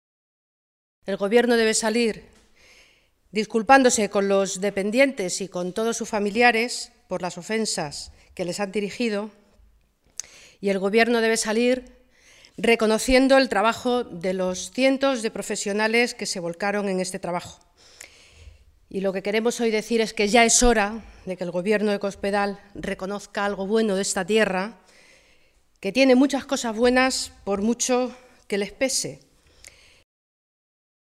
Guadalupe Martín, diputada Nacional del PSOE
Cortes de audio de la rueda de prensa